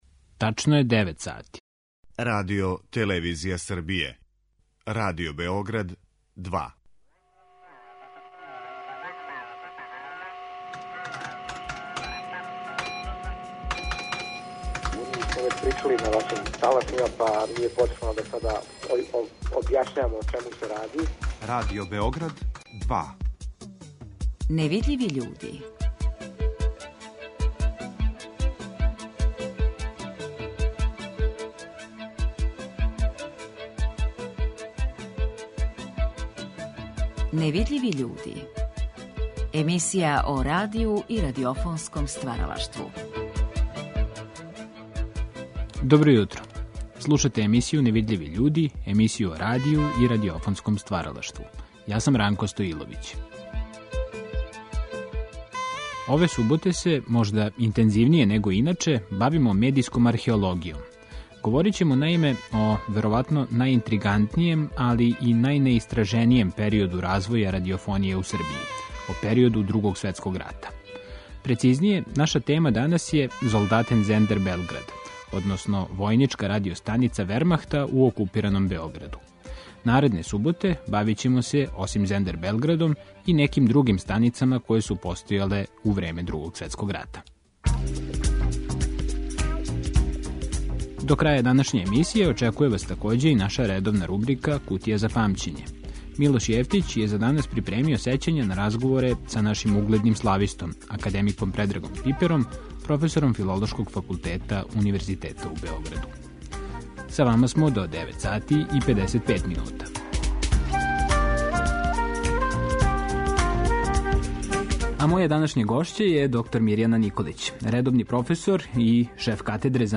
Чућете и ретке снимке из Тонског архива Радио Београда који датирају из овог периода, или се, кроз потоња сећања директних актера, реферишу на њега.